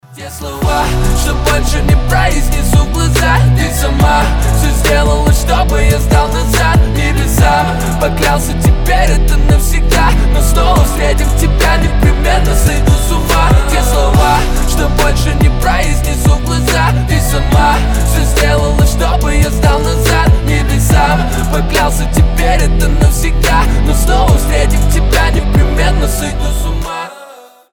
• Качество: 320, Stereo
мужской голос
лирика